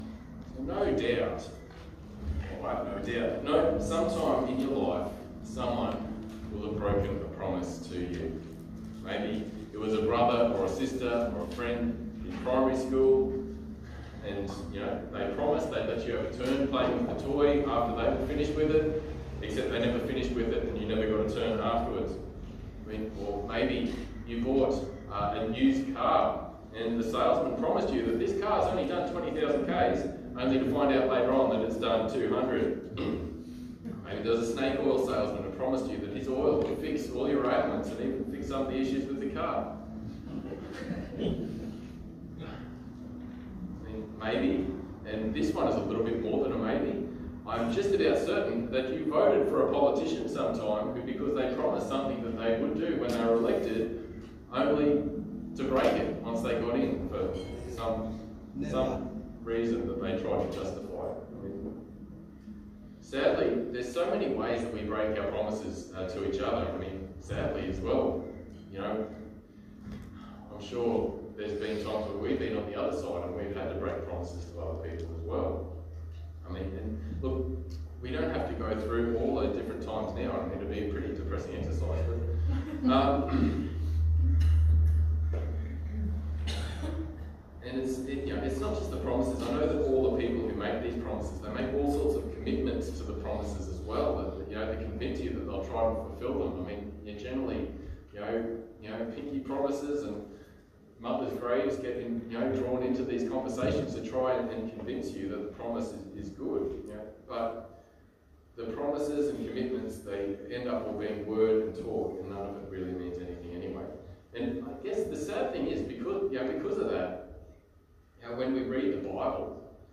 Passage: Genesis 15:7-21 Service Type: Sunday Morning